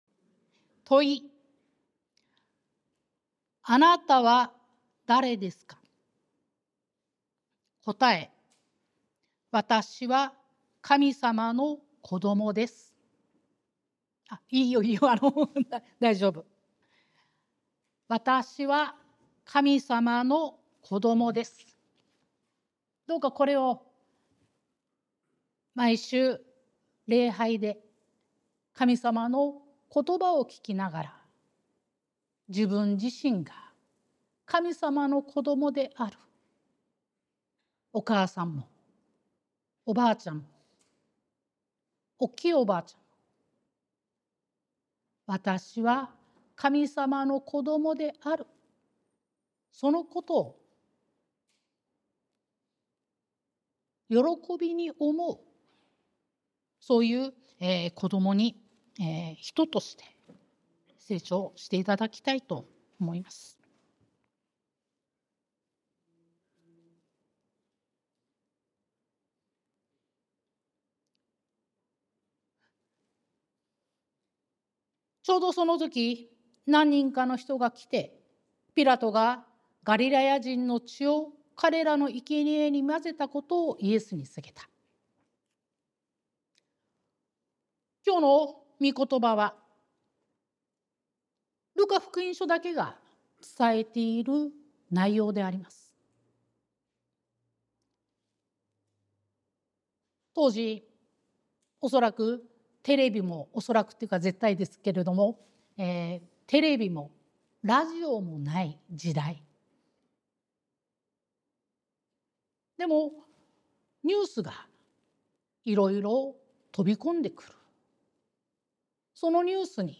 sermon-2025-03-16